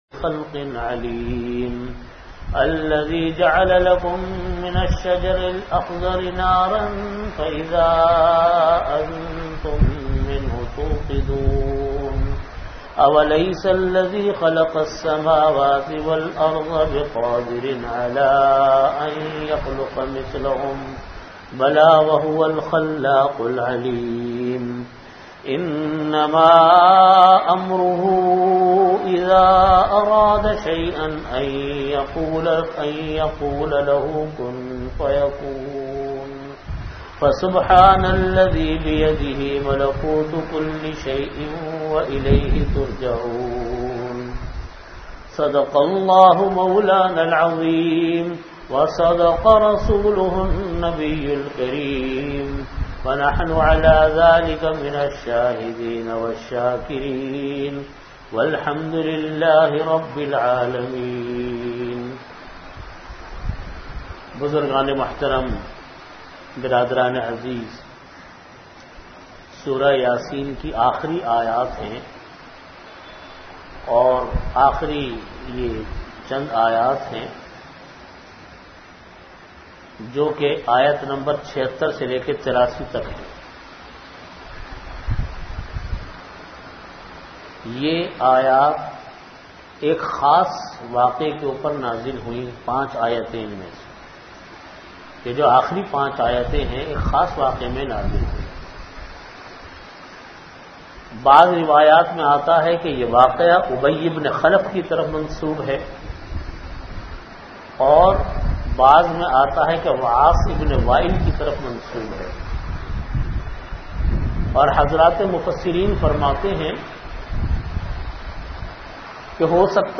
Time: After Asar Prayer Venue: Jamia Masjid Bait-ul-Mukkaram, Karachi